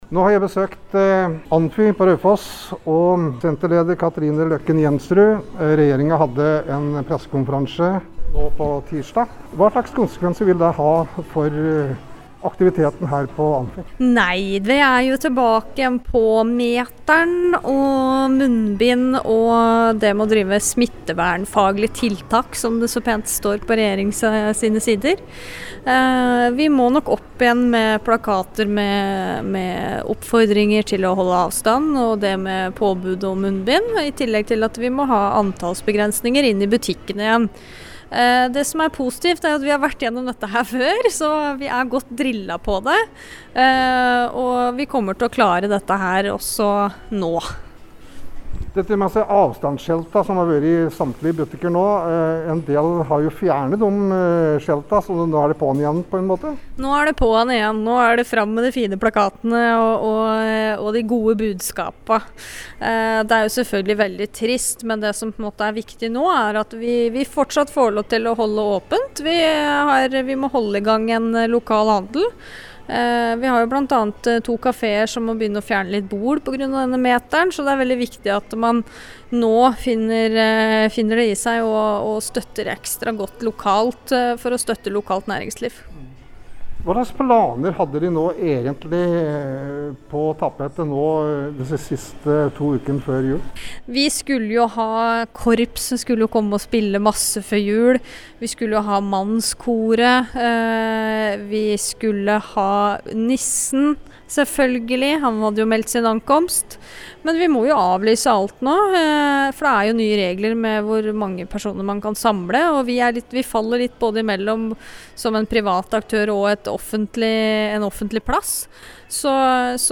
Innslag
Vi har tatt en tur til Amfi for å sjekke hvordan den ny situasjonen er etter at regjeringen innførte nye tiltak på tirsdag.